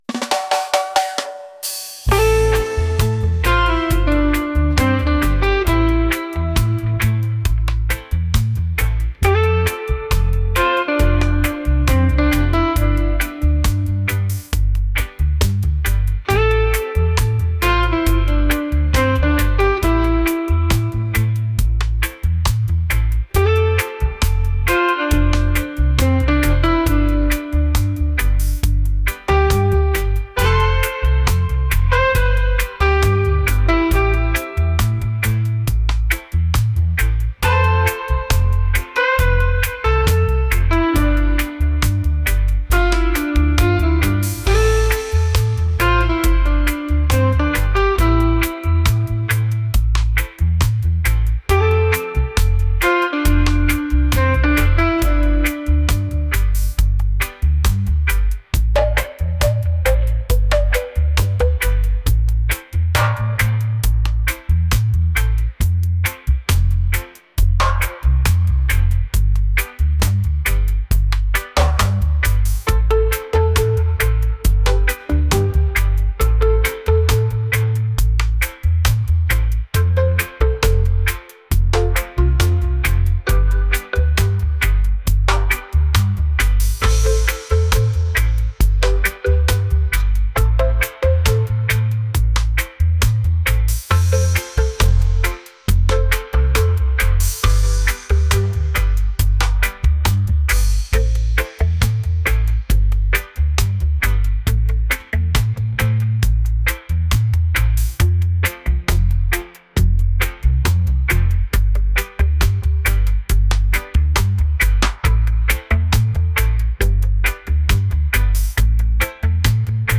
reggae | romantic